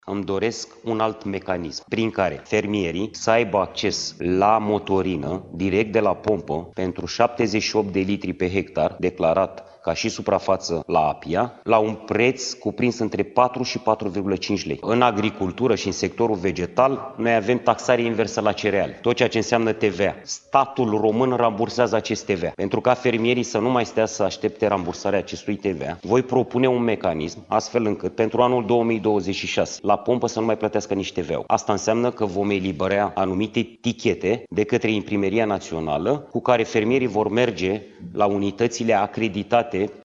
Ministrul Agriculturii, Florin Barbu, a spus la Agro TV că va propune Guvernului schimbarea mecanismului actual, iar fermierii nu vor mai aștepta returnarea TVA.